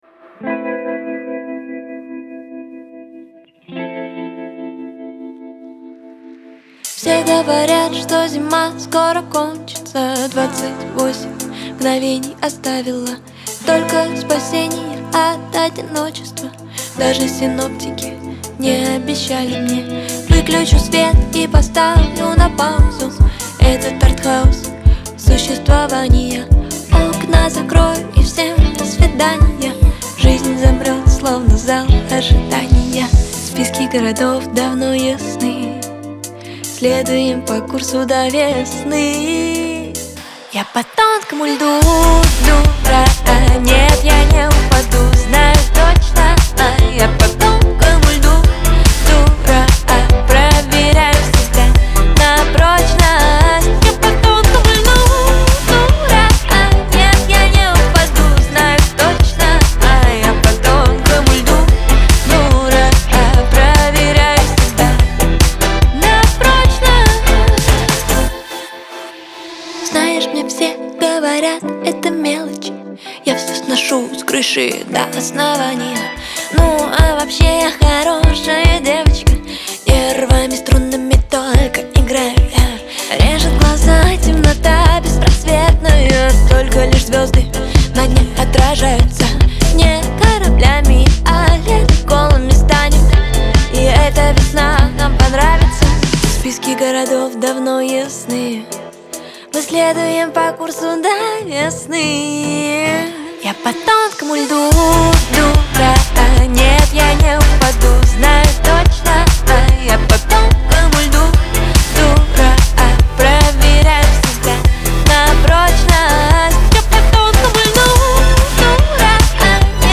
выполненная в жанре альтернативного рока.